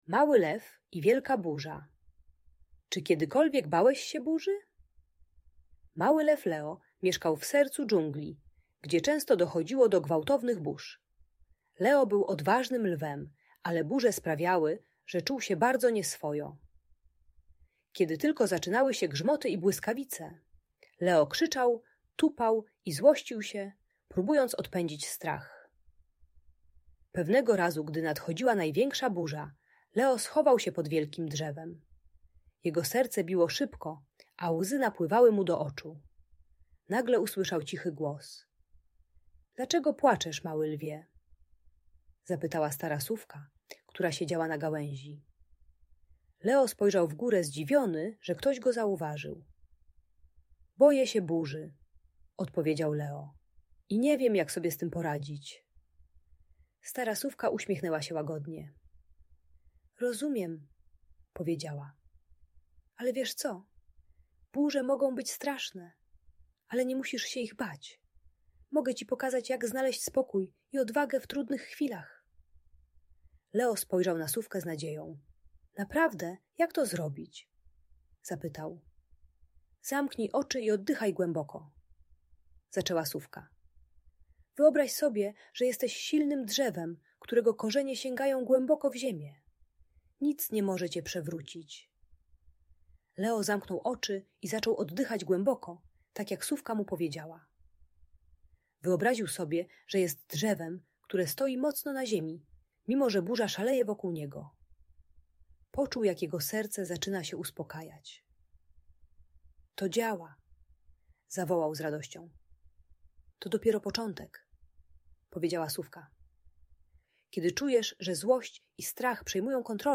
Uczy techniki wizualizacji "silnego drzewa" połączonej z głębokim oddychaniem, by uspokoić strach i złość. Audiobajka o radzeniu sobie z trudnymi emocjami gdy dziecko krzyczy i tupie ze strachu lub złości.